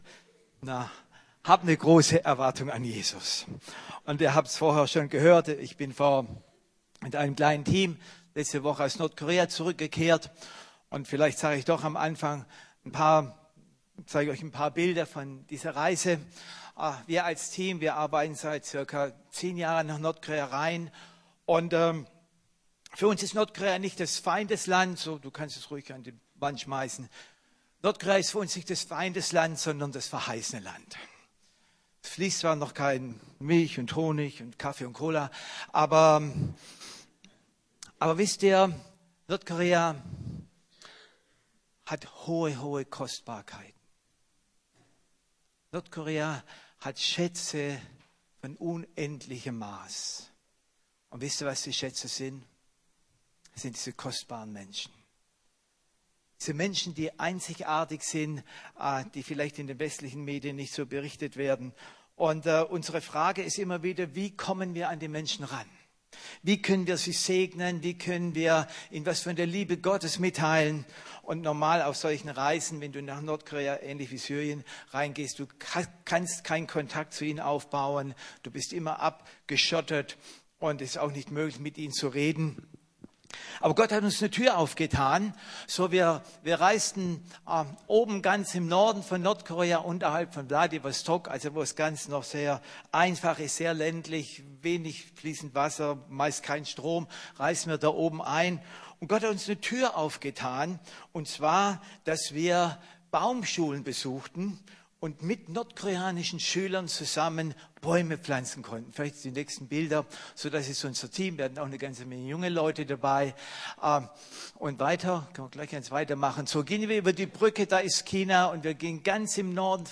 Die Heilung der Städte und Nationen ~ Predigten der LUKAS GEMEINDE Podcast